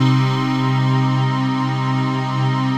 CHRDPAD057-LR.wav